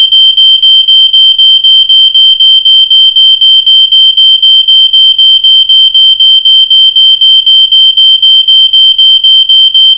Aus diesem Grund haben wir die Warnmelder Alarme zahlreicher aktueller Modelle für Sie aufgezeichnet.
ei650w-funkrauchmelder-alarm.mp3